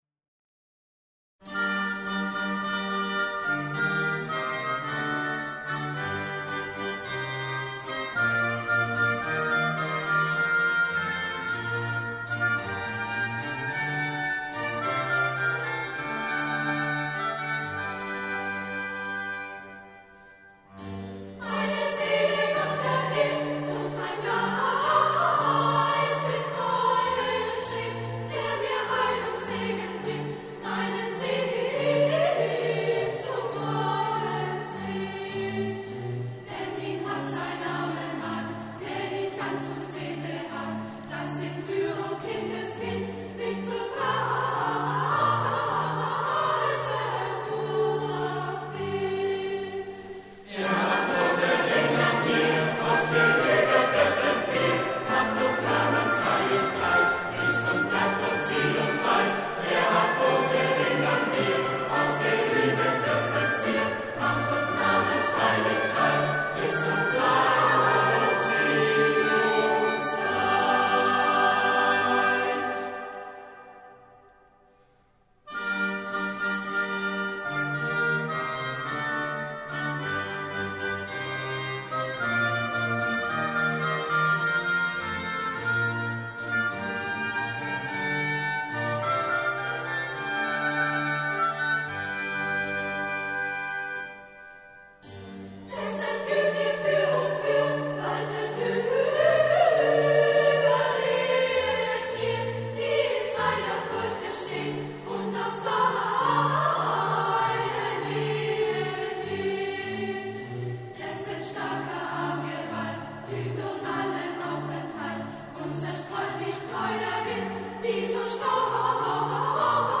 in der Entenfußhalle des Klosters Maulbronn
Kantate für Soli, Chor und kleines Orchester